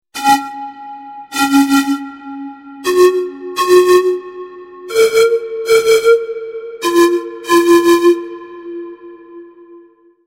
Poniżej zamieszczono przykładowe dźwięki otrzymane przy pomocy modelu fizycznego.
efekt tremolo